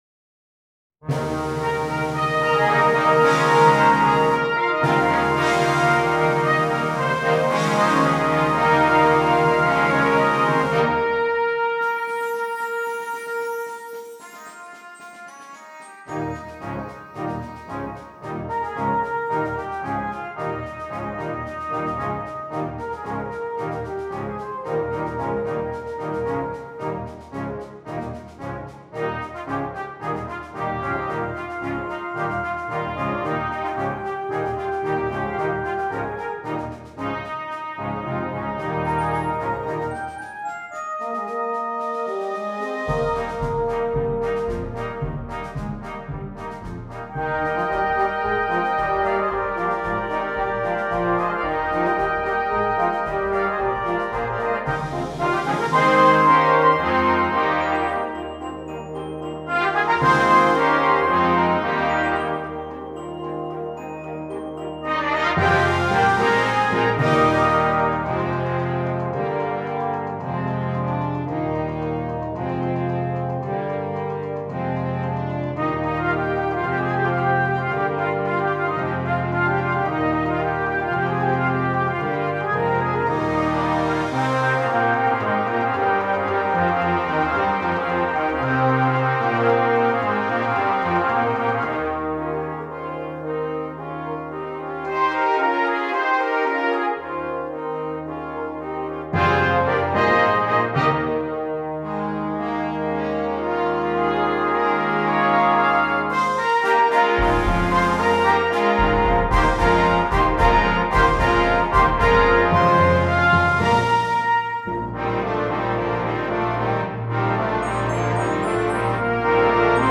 Brass Choir (6.4.3.1.1.perc)